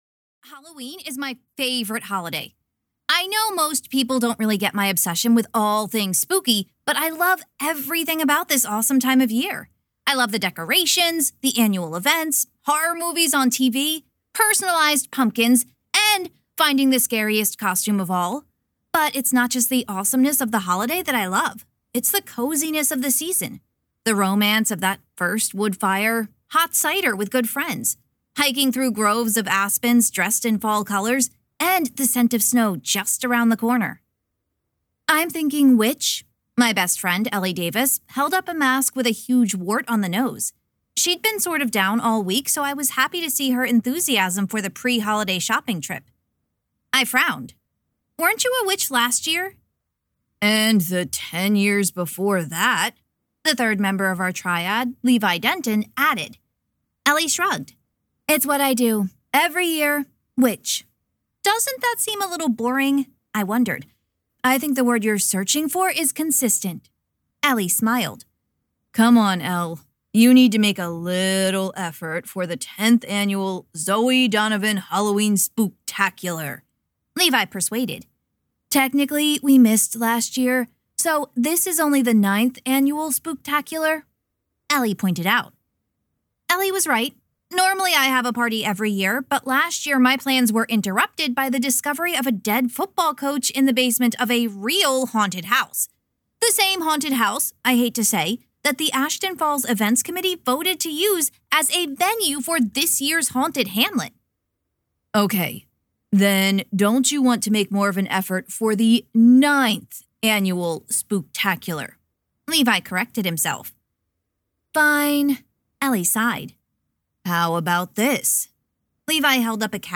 • Audiobook
Book 9 Retail Audio Sample Haunted Hamlet Zoe Donovan Mystery.mp3